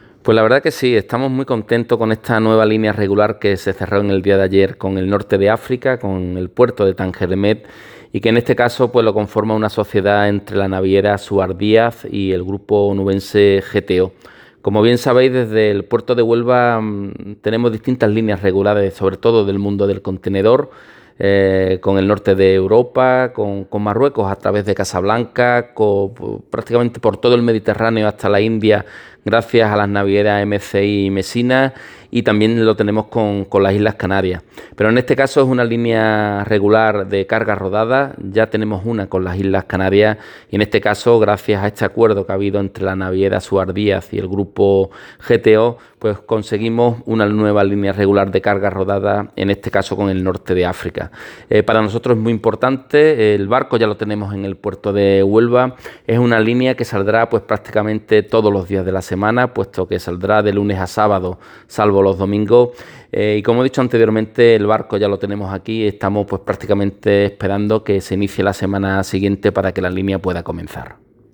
🔊 Alberto Santana, presidente del Puerto de Huelva